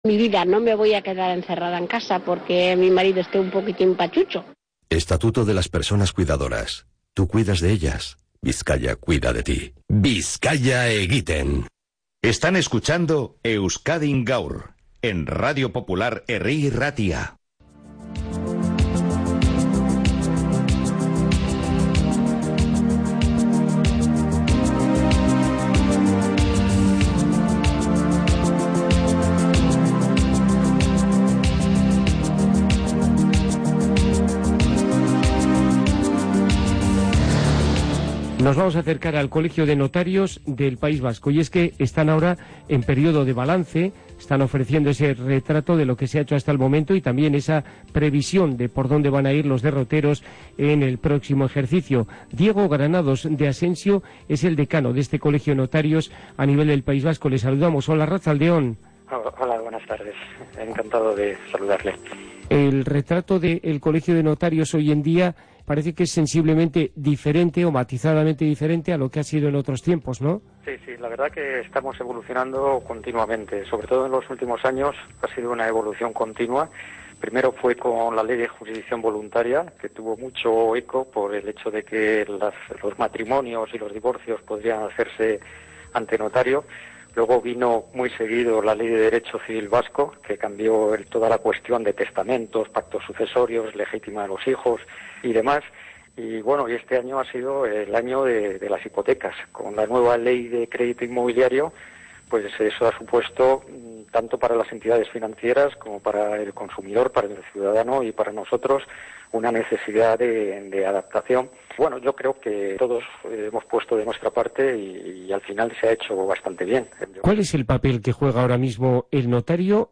Si quieren escuchar la entrevista pueden descargar el corte de radio pinchando en el siguiente enlace: